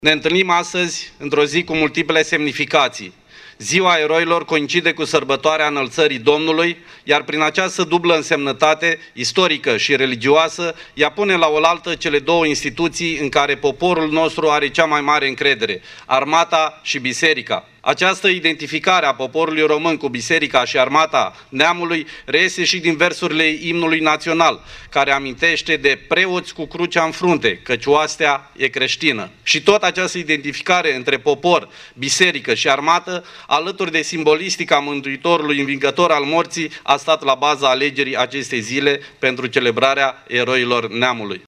Autorităţi locale şi judeţene din Iaşi au marcat, astăzi, Ziua Eroilor, la monumentul din Cimitirul Eternitatea, la cel din dealul Galata şi la cimitirul eroilor căzuţi în al Doilea Război Mondial de la Leţcani.
Prefectul Marian Şerbescu a declarat că după prima conflagraţie mondială, România a fost primul stat care a recunoscut importanţa comemorării eroilor indiferent de naţionalitatea acestora: